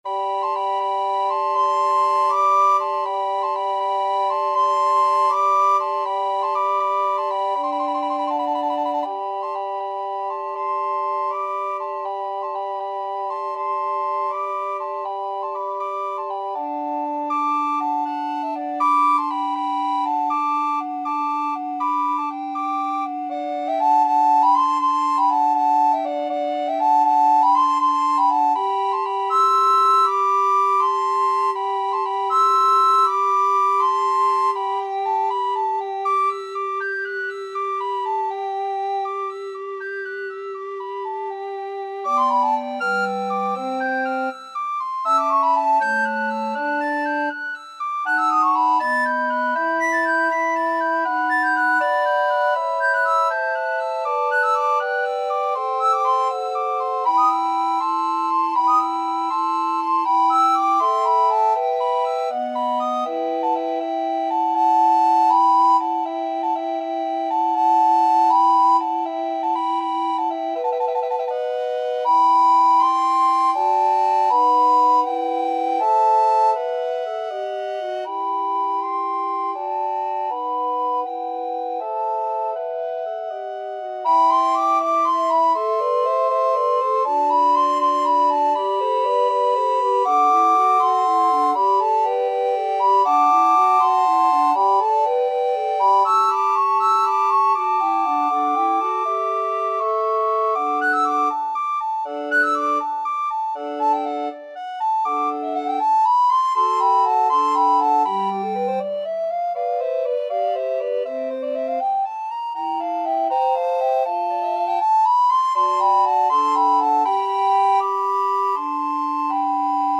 Soprano RecorderAlto RecorderTenor RecorderBass Recorder
Allegro (View more music marked Allegro)
12/8 (View more 12/8 Music)
Classical (View more Classical Recorder Quartet Music)